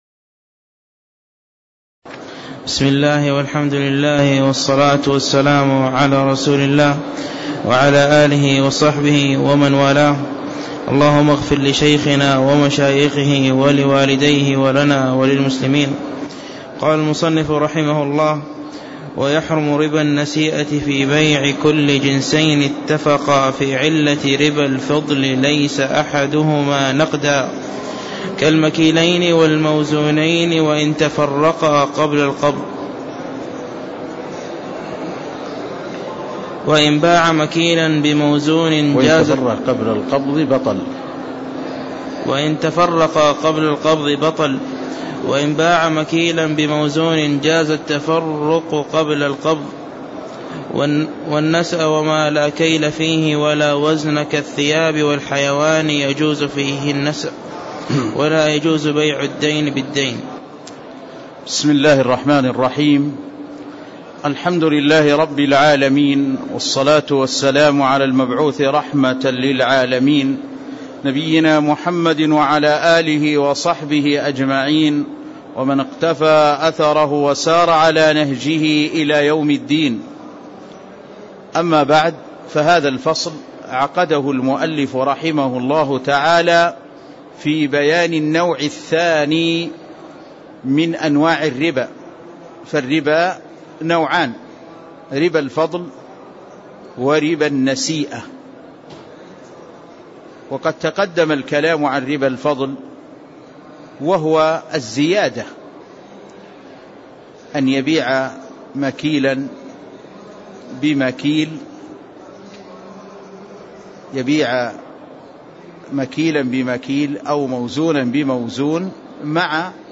تاريخ النشر ٢١ رجب ١٤٣٦ هـ المكان: المسجد النبوي الشيخ